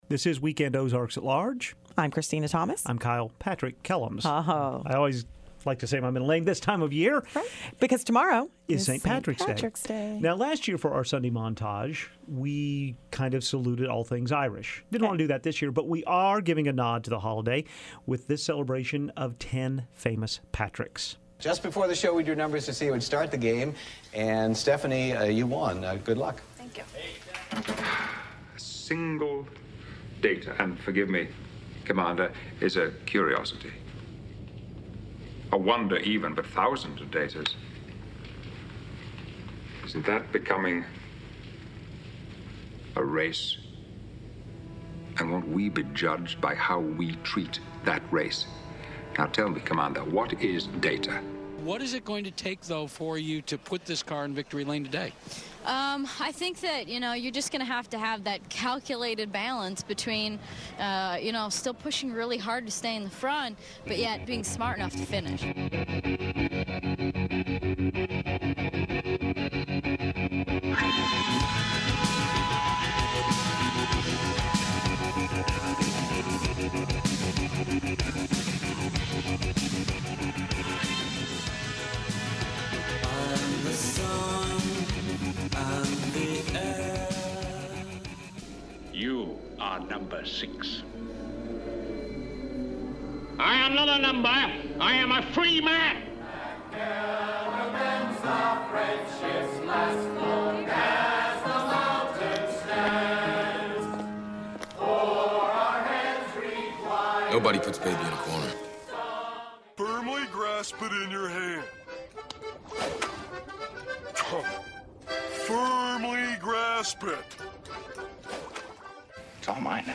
1. Pat(rick) Sajak spins the Wheel of Fortune.
3. Danica Patrick answers a pre-race question.
4. The Smiths, including Steven Patrick Morrissey, sing How Soon is Now.
10. Orson Welles on a vinyl record delivers Patrick Henry's speech.